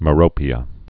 (mə-rōpē-ə)